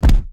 block_large_71.wav